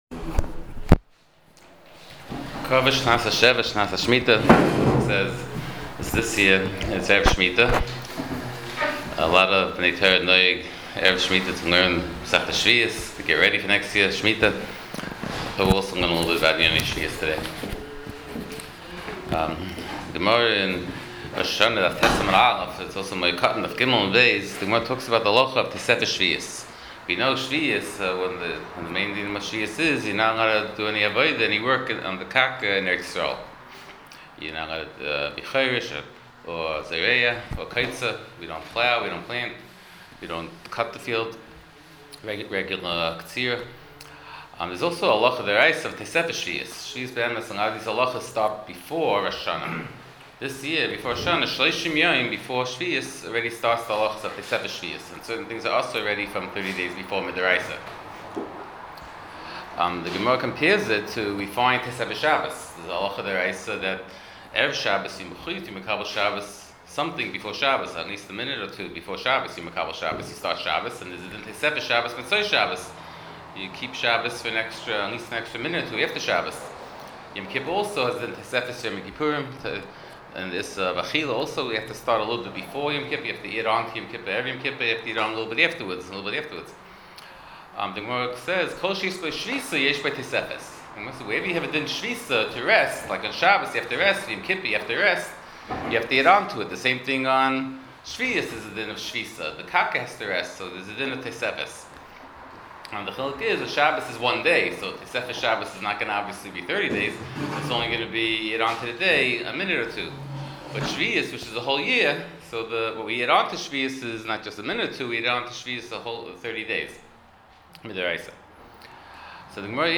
On Shmitah and Erev Shmitah Shiur provided courtesy of Madison Art Shop.